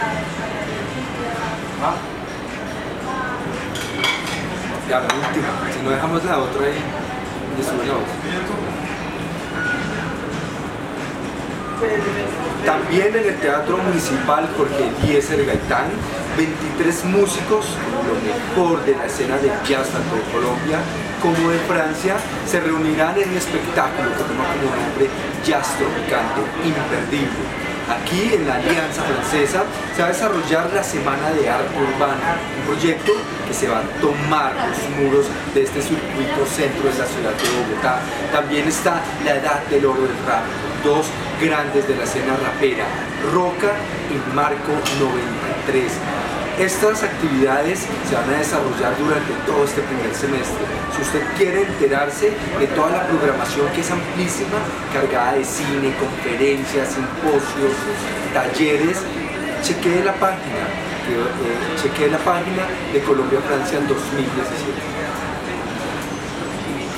Programas de radio